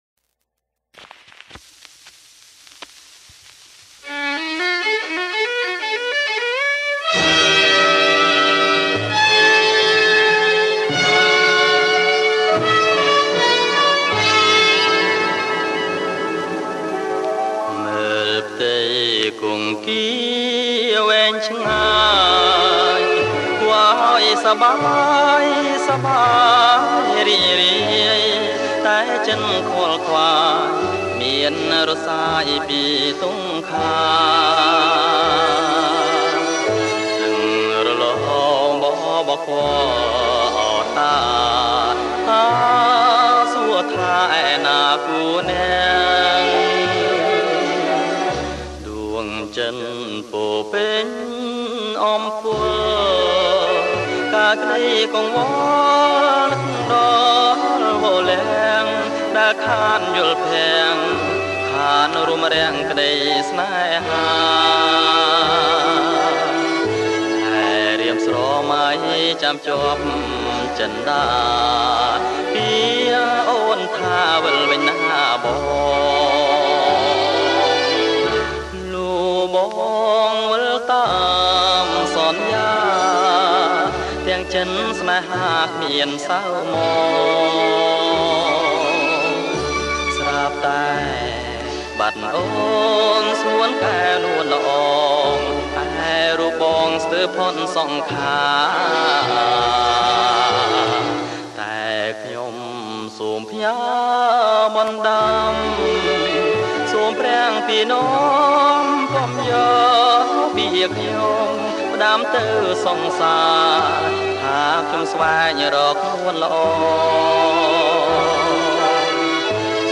• ចម្រៀងដកស្រង់ពីខ្សែភាពយន្ត
• ប្រគំជាចង្វាក់ Slow Rock